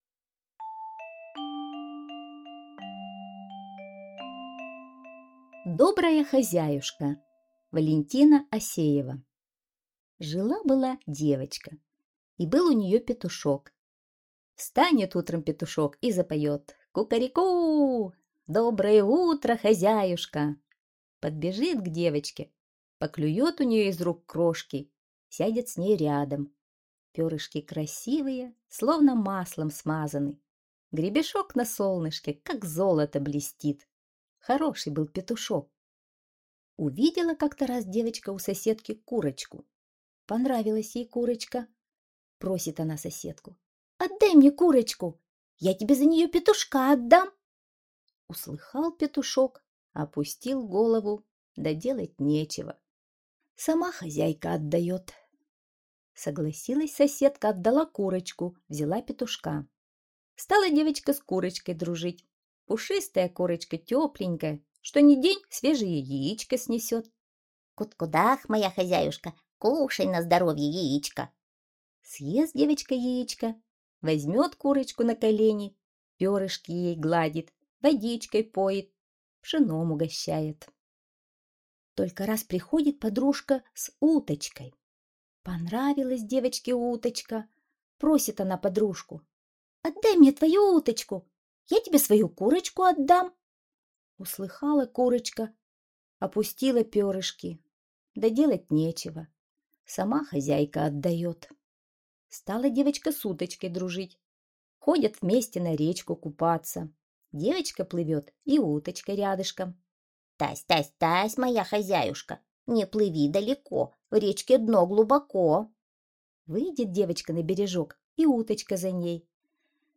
Добрая хозяюшка - аудиосказка Осеевой В.А. Сказка про девочку, которая не умела дорожить дружбой с легкостью меняла своих животных.